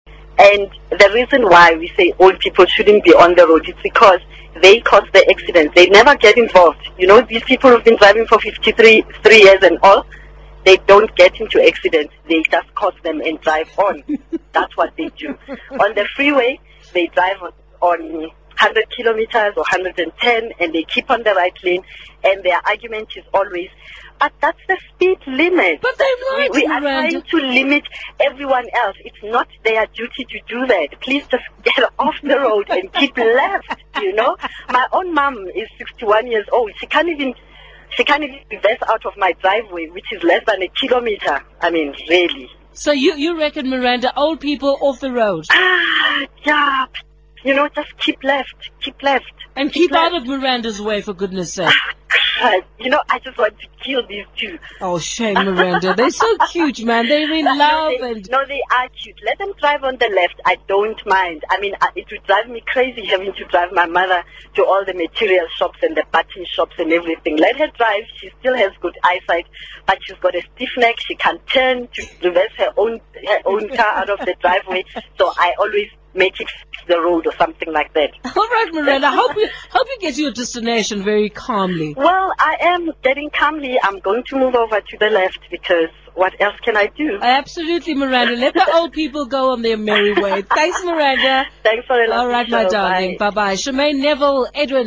Black South African English
The varieties which have arisen here show a considerable influence from the native background languages of speakers. For instance, typical features of Bantu languages emerge in Black South African English, e.g. syllable-timing, mid-length monophthongs and r-lessness.
SouthAfrica_Black.wav